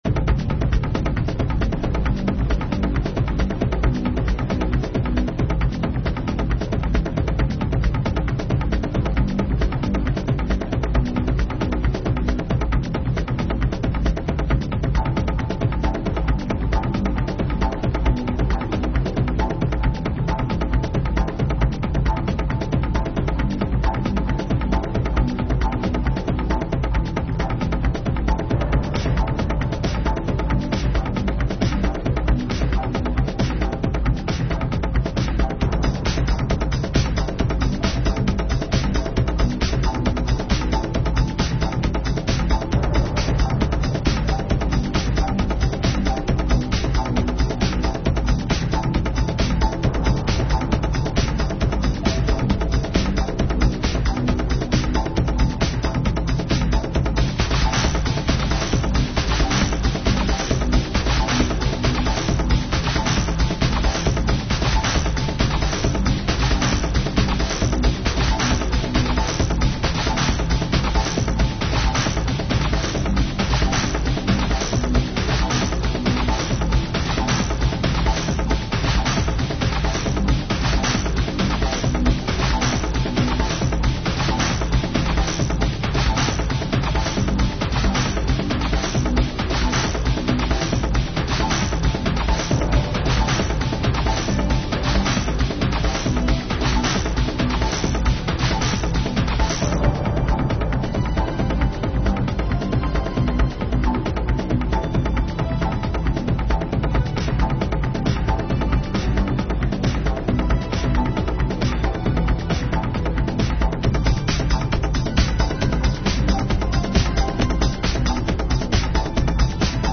dance/electronic
House
Techno